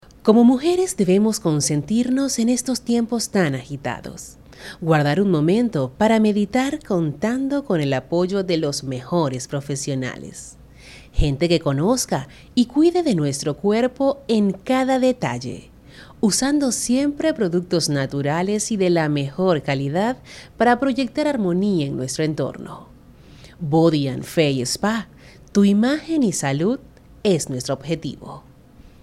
Locutora profesional, voz marca, voz institucional, productora, narradora de noticias.
Sprechprobe: eLearning (Muttersprache):